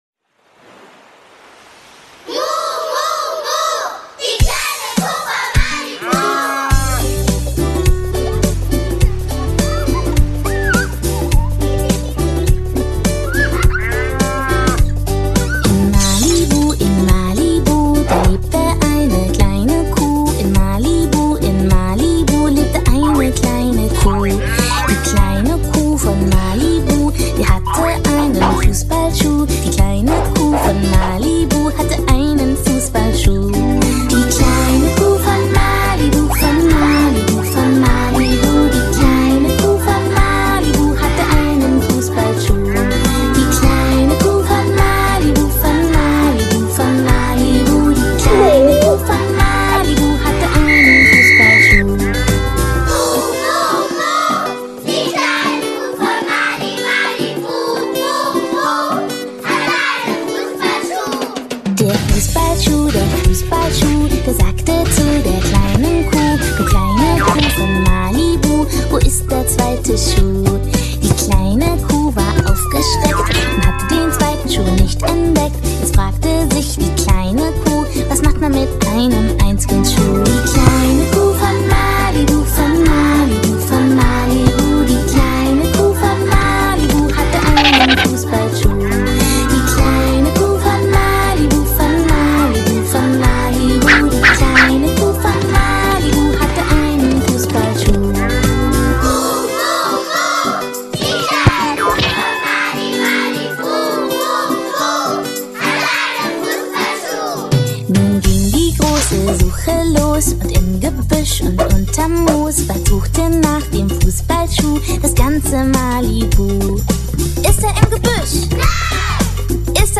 从欢快的童声里，